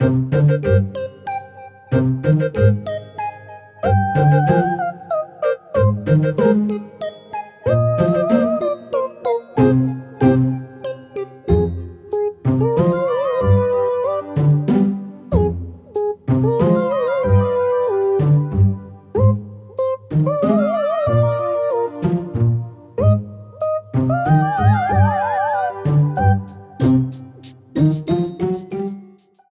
Ripped from the game files
applied fade-out on last two seconds when needed